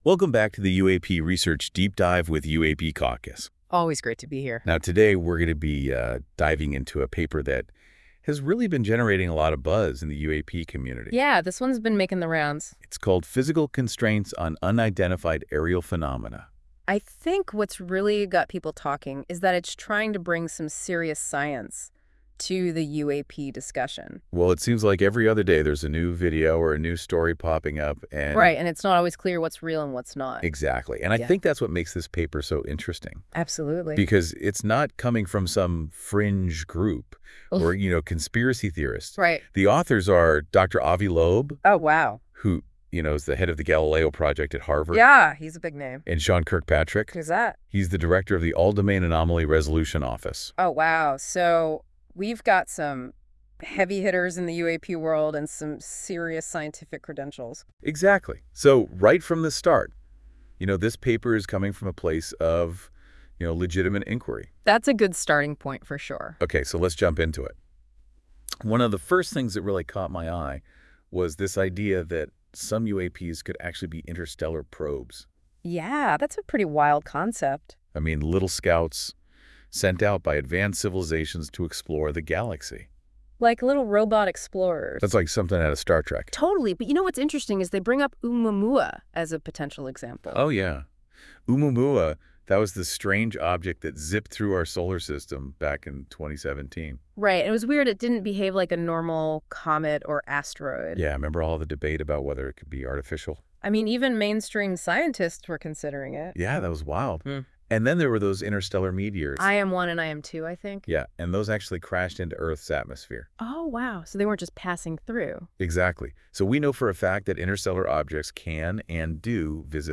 This AI-generated audio may not fully capture the research's complexity.